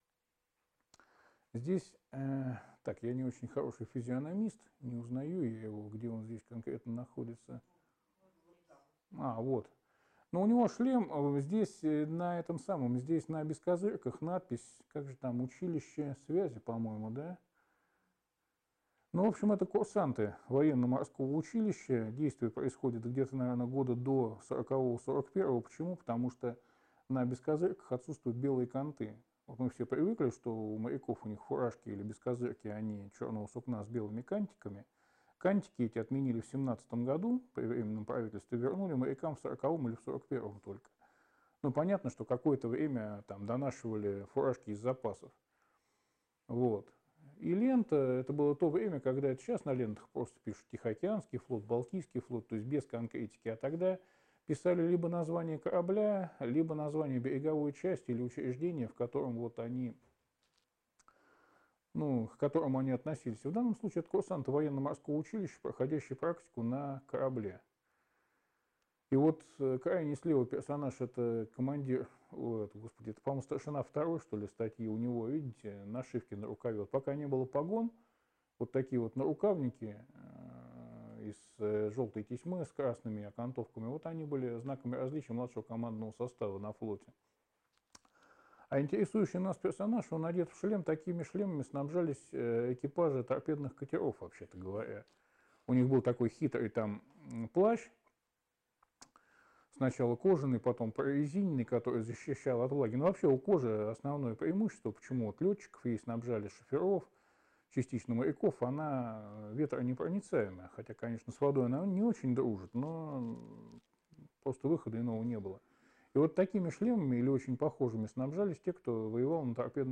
3. «Сеансы связи» в Доме Белявского